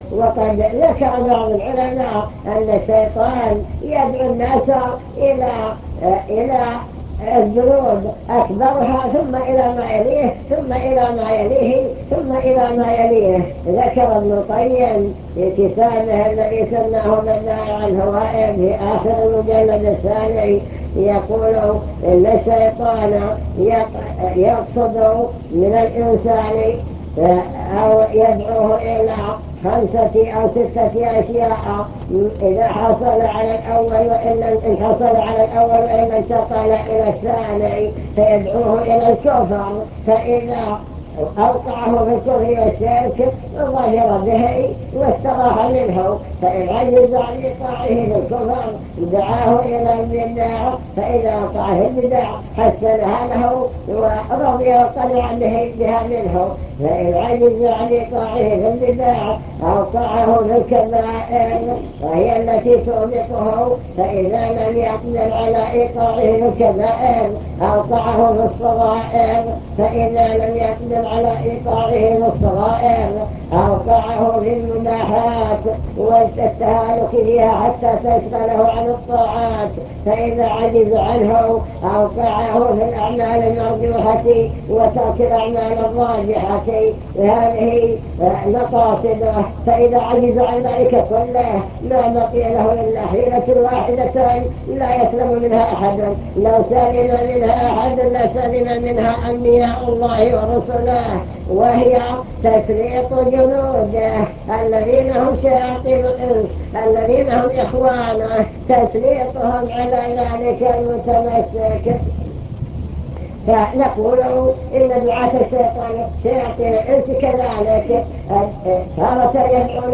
المكتبة الصوتية  تسجيلات - محاضرات ودروس  محاضرة في فتن هذا الزمان ومقاومتها